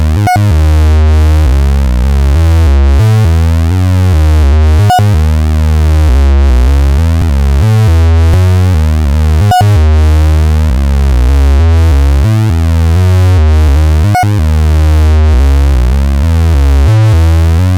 Synth Test